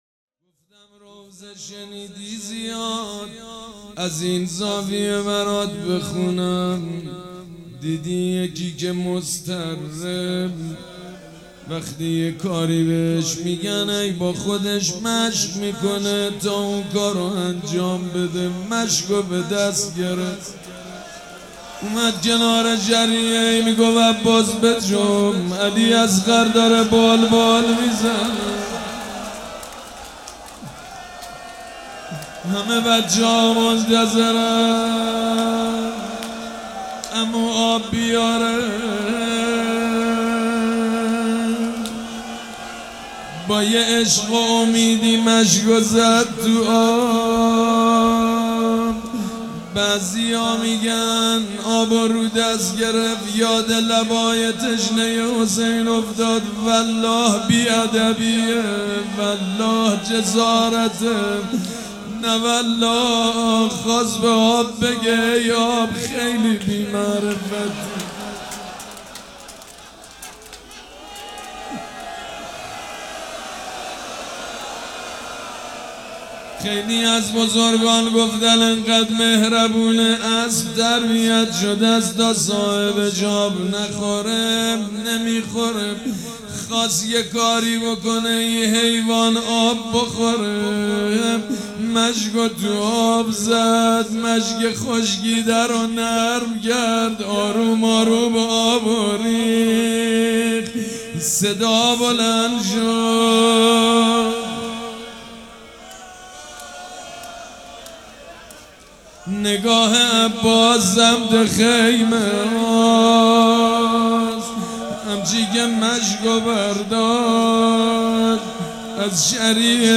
روضه بخش دوم
شب تاسوعا محرم 98
سبک اثــر روضه
مراسم عزاداری شب تاسوعا